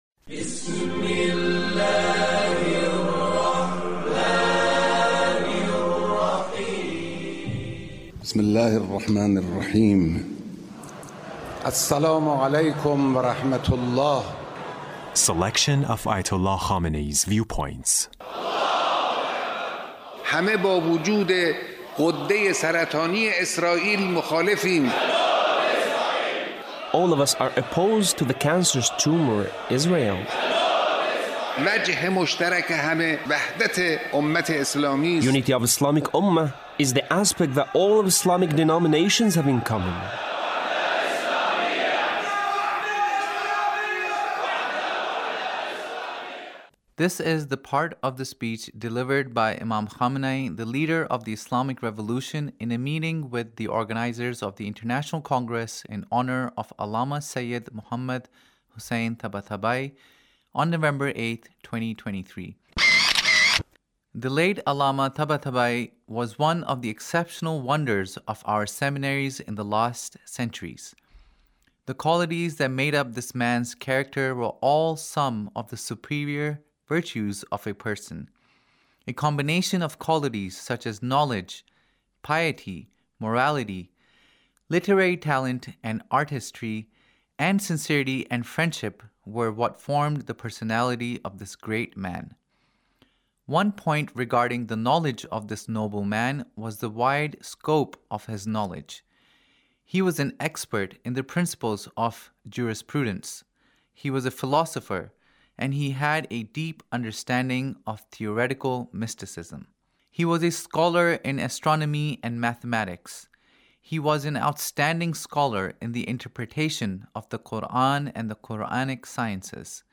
Leader's Speech (1871)
Leader's Speech about Allame Tabatabaee